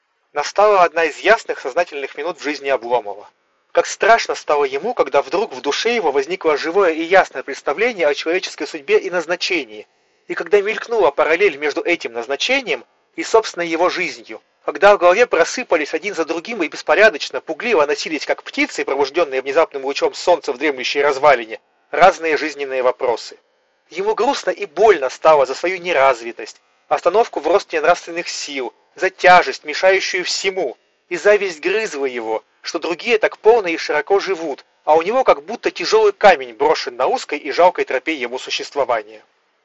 На первом речь записана с помощью микрофонов гарнитуры.
Запись с микрофона гарнитуры
Скажем прямо, высоких ожиданий наушники не оправдали: голос звучит зажато, с явным «телефонным эффектом». Но при этом достаточно разборчиво, чтобы ответить на звонок и перекинуться с собеседником парой-тройкой фраз.